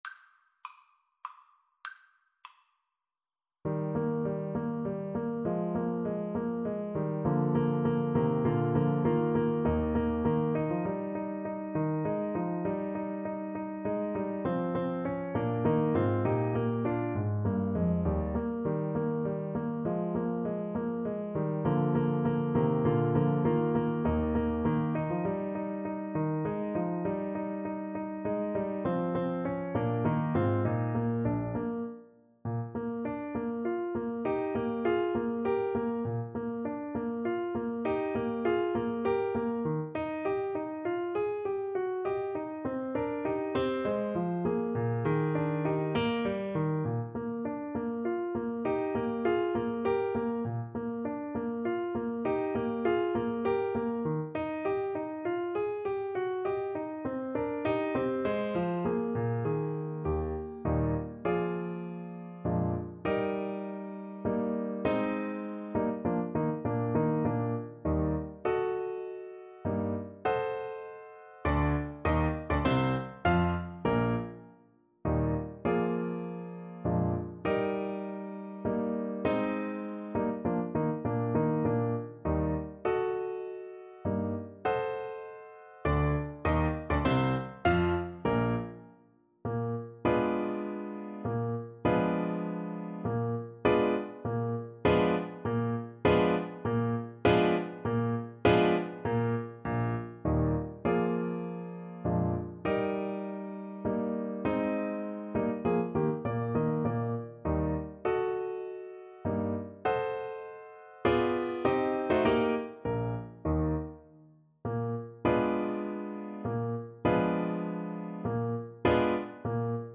• Unlimited playalong tracks
3/4 (View more 3/4 Music)
Menuetto Moderato e grazioso
Classical (View more Classical Violin Music)